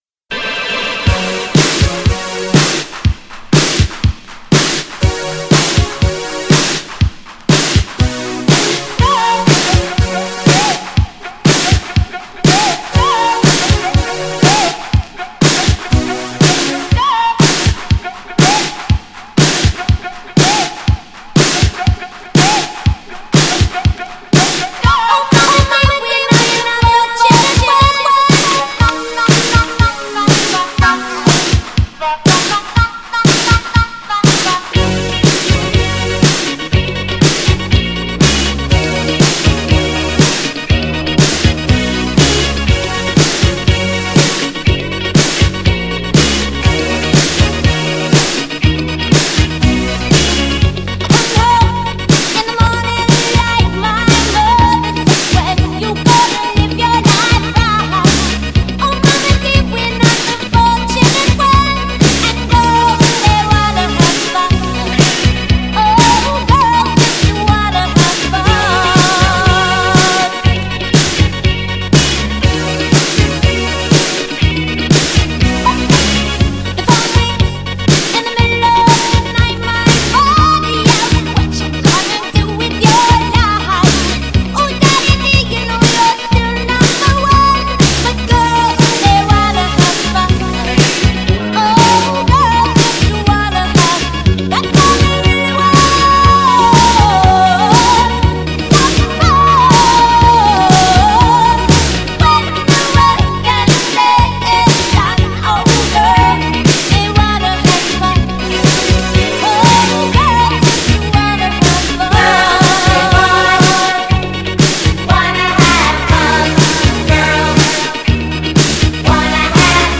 GENRE Dance Classic
BPM 66〜70BPM
エモーショナル # スロー # ニューウェーブ # 切ない感じ # 泣き # 洗練された